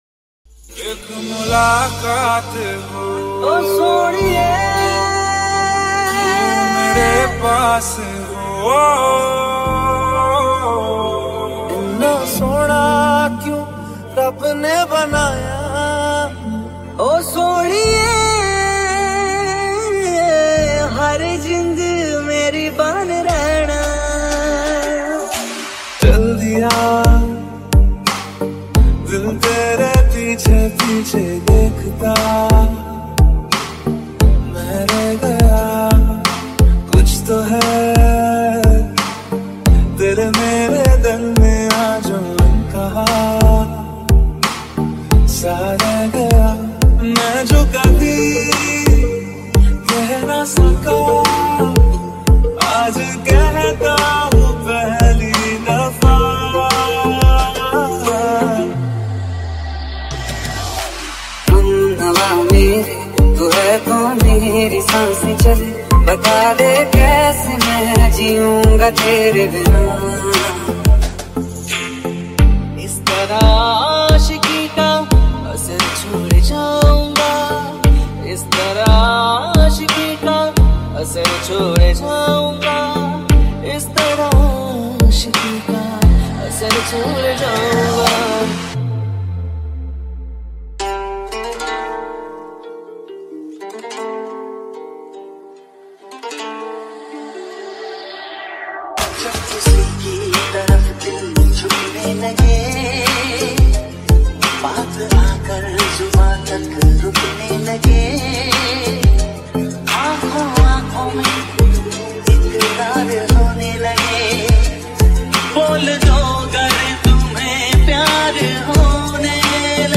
Hindi Romantic Songs